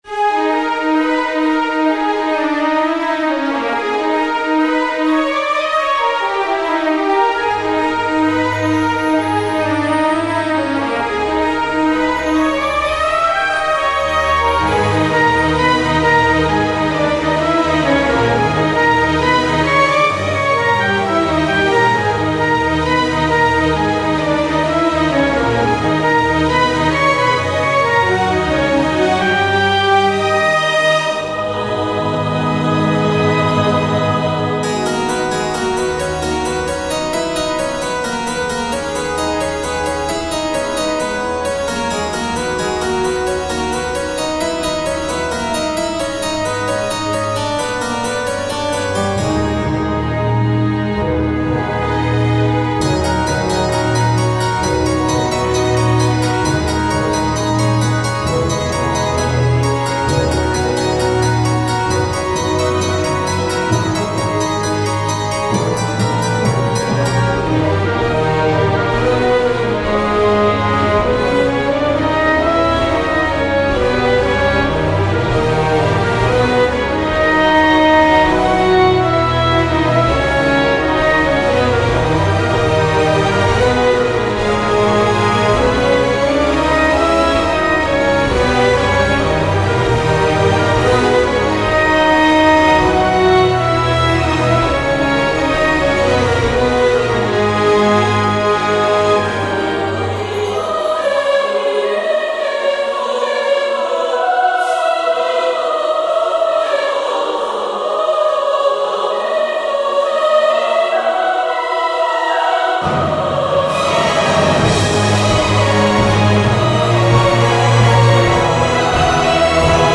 ジャンルオーケストラ
BPM１３２
使用楽器バイオリン、ブラスセクション、チェンバロ、パイプオルガン、合唱団
恐ろしき戦いに身を投じなければならない心情を演出しております。
戦闘曲(Battle)
オーケストラ/室内楽(Orchestra/chamber)